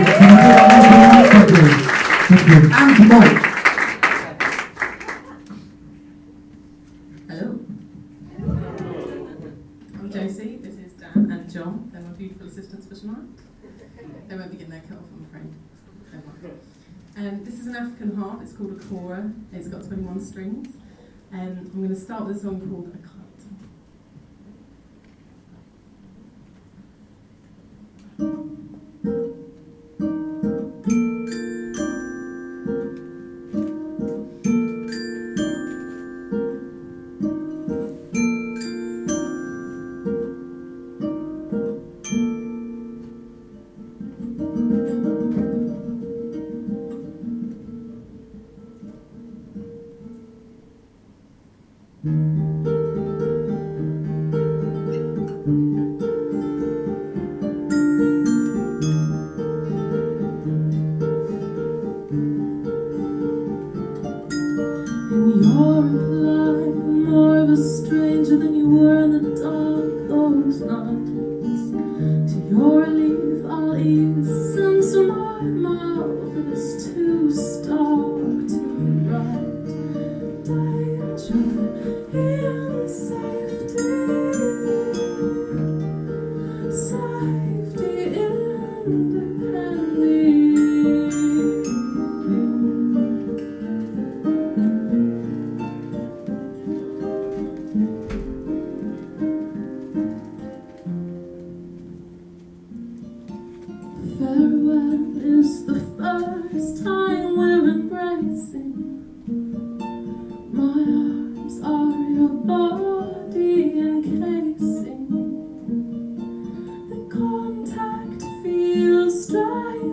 More music at the Cavendish